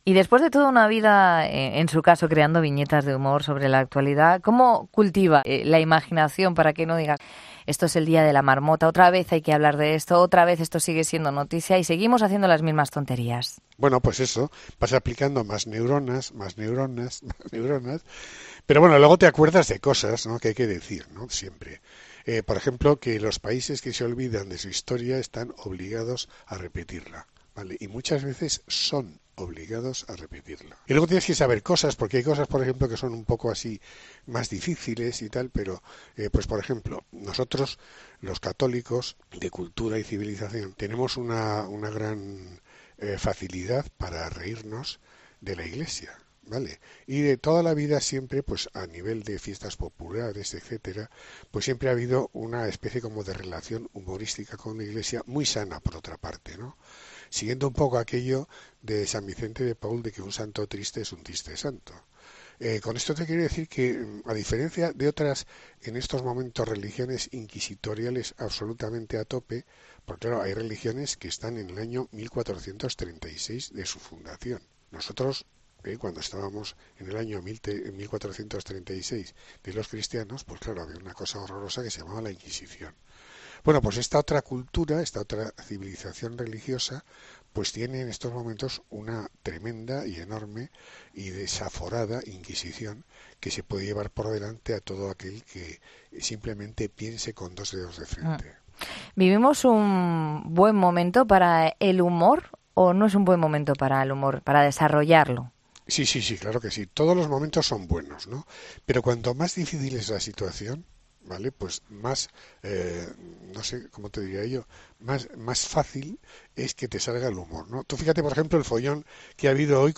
Entrevista de Forges en COPE en 2015